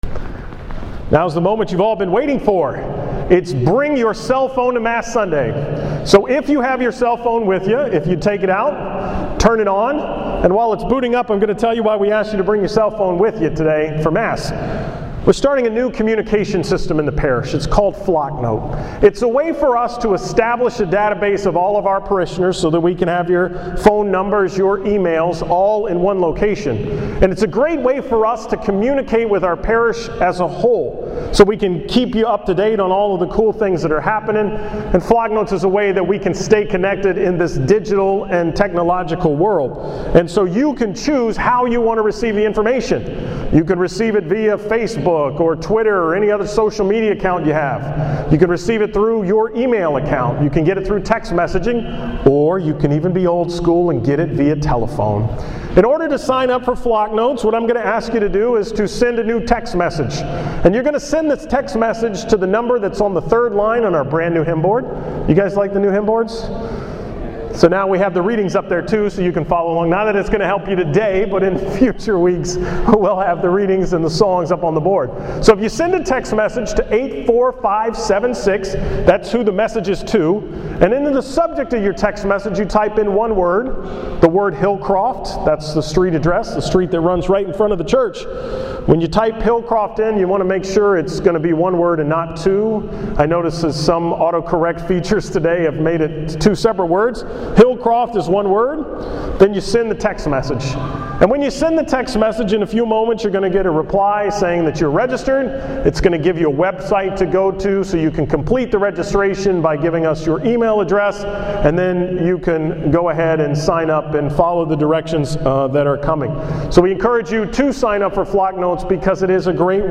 From the 11 am Mass on November 3, 2013